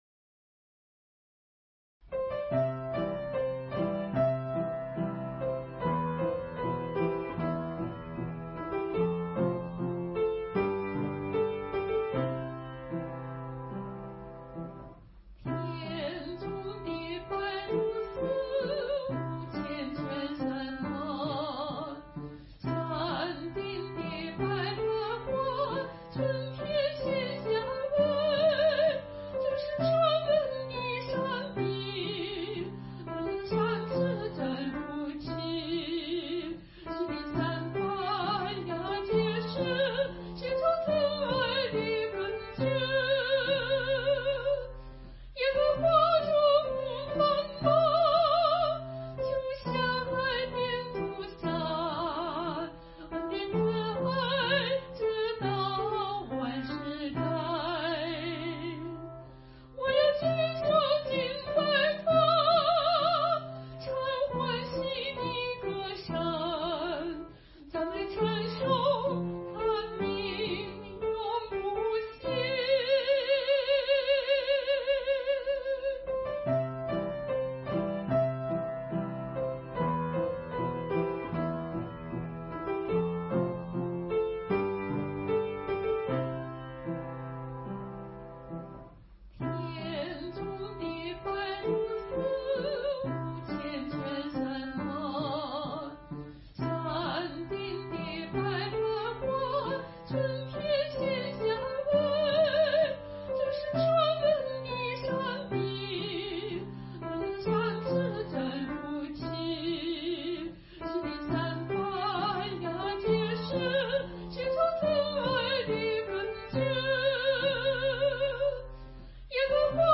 705伴奏